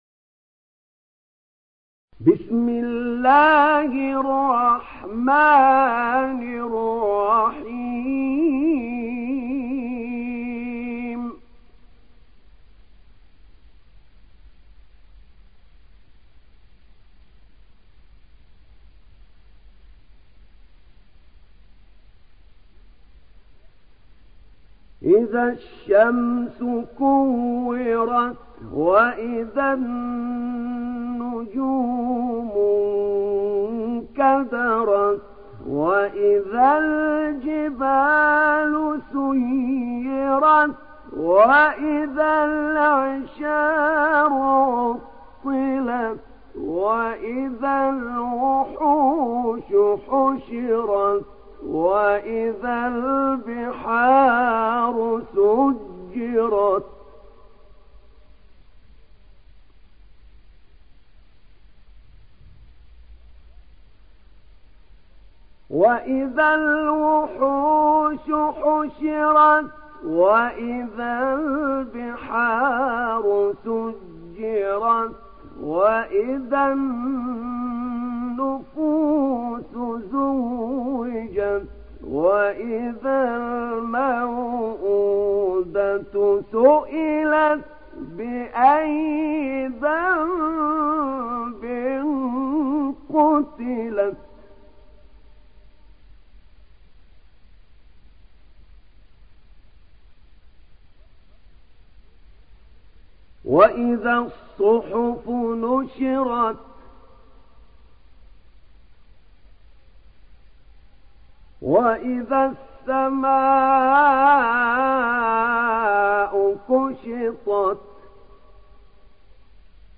تحميل سورة التكوير mp3 بصوت أحمد نعينع برواية حفص عن عاصم, تحميل استماع القرآن الكريم على الجوال mp3 كاملا بروابط مباشرة وسريعة